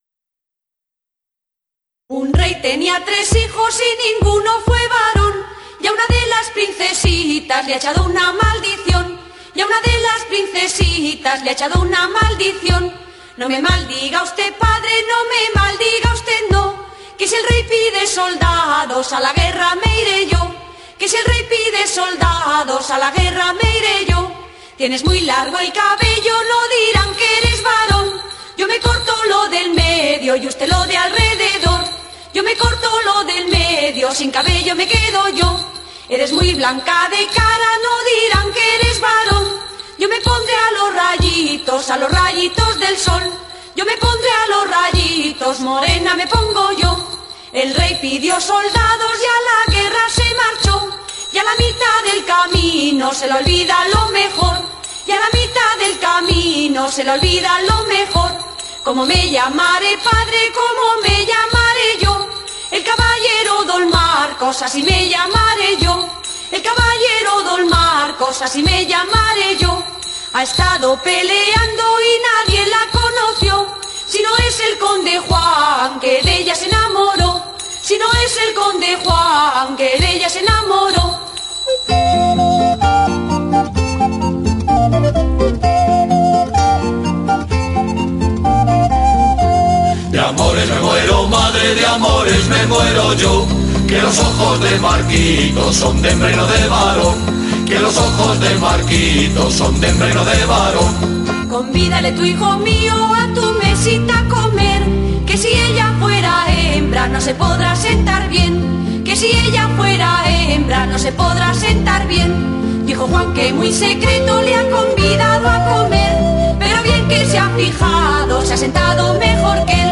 Un ejemplo es "El romance de Marquitos" que puedes escuchar aquí en forma de canción. Es un romance muy parecido al de "La doncella guerrera" que tendrás que leer en la siguiente actividad.